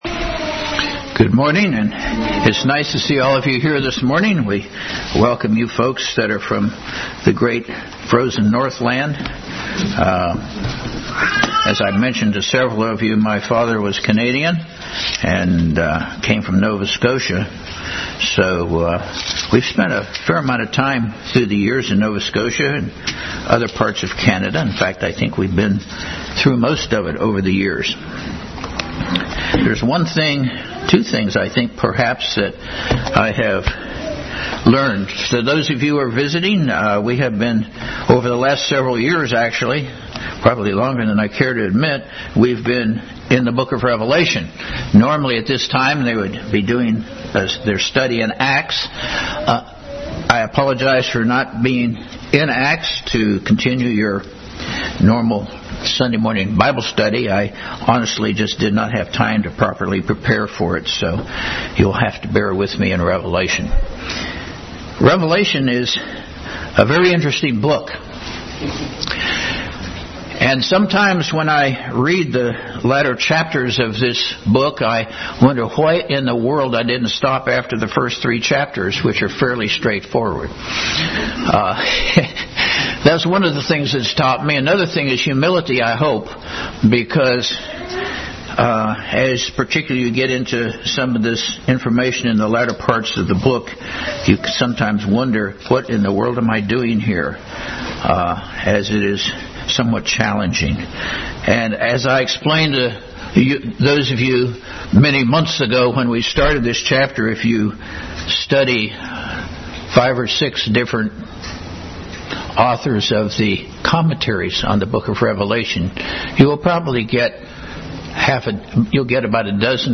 Revelation 17:1-18 Service Type: Sunday School Bible Text